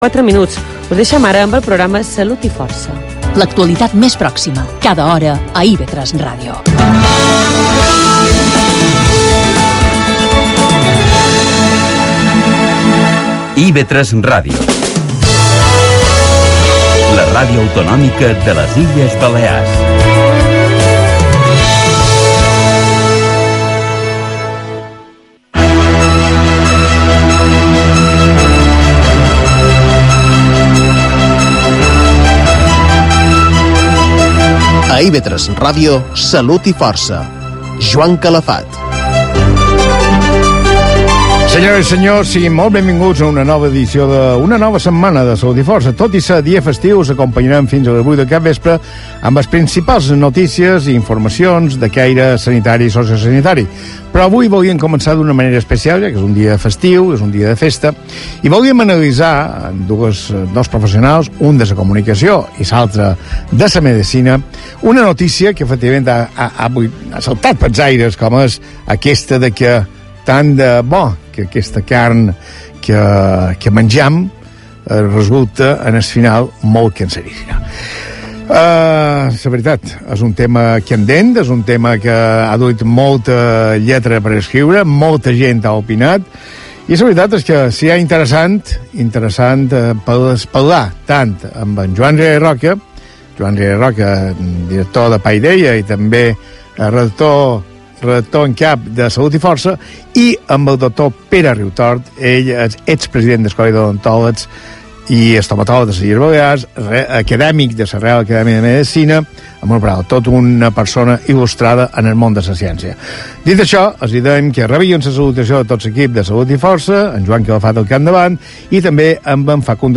El pasado 16 de Noviembre intervine en la radio autonómica de las Islas Baleares, IB3. De nuevo, una deliciosa entrevista en la que me sentí muy a gusto y que os invito a escuchar:
Mi intervención empieza en el minuto 36.00: